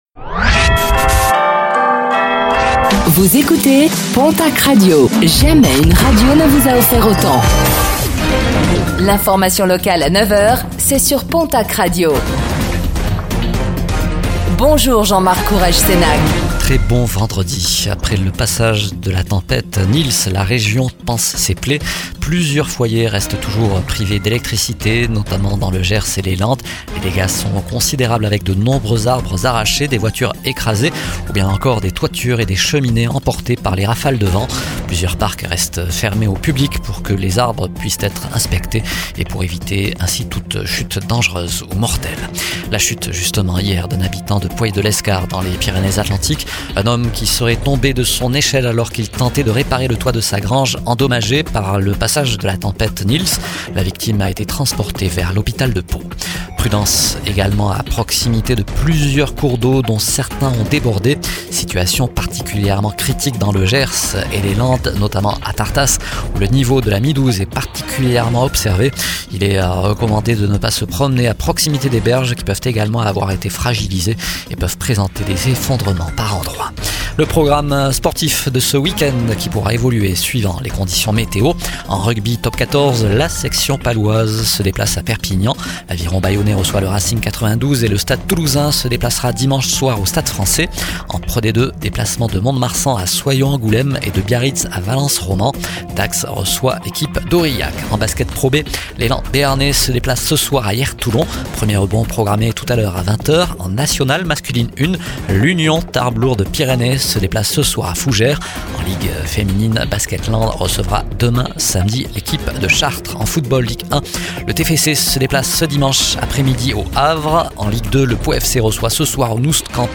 Réécoutez le flash d'information locale de ce vendredi 13 février 2026